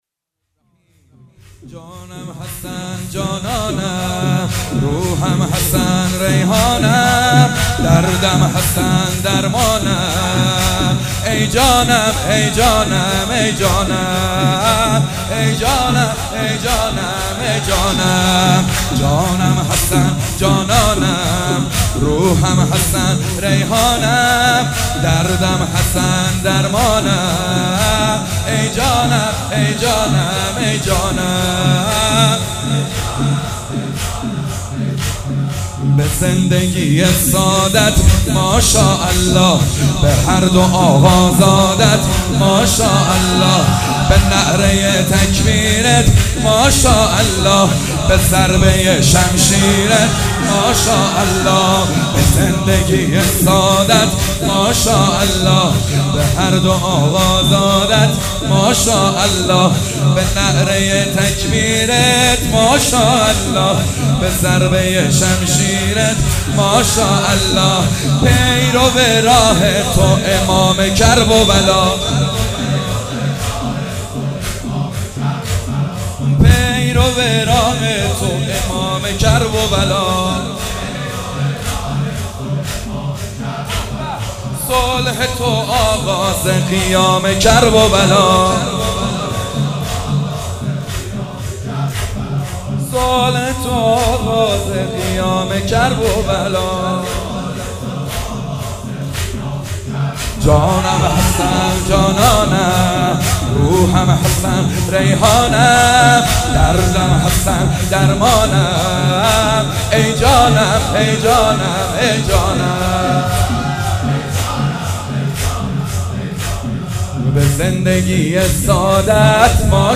گلچین مداحی های رحلت پیامبر اکرم صل الله علیه و آله